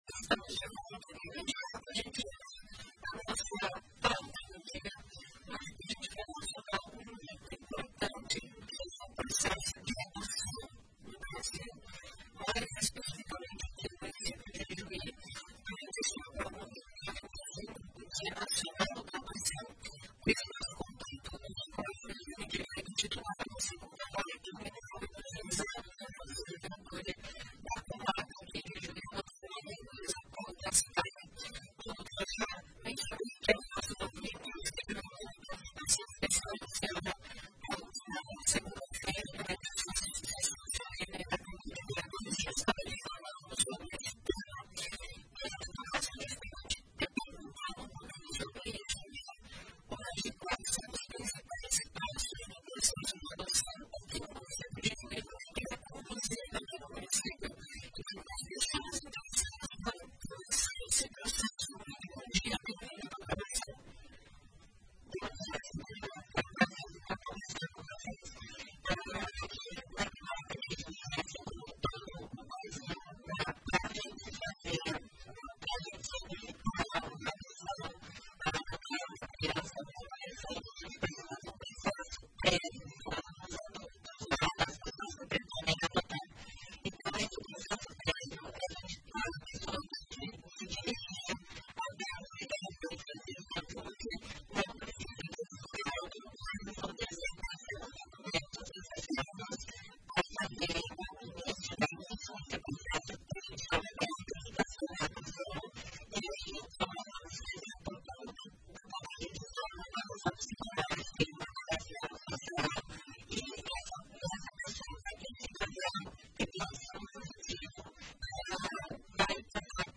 Para marcar a data, a Rádio Progresso conversou com a Juíza de Direito, titular da 2ª Vara Criminal e do Juizado da Infância e Juventude da comarca de Ijuí, Maria Luiza Pollo Gaspary, que trouxe detalhes sobre o processo de adoção.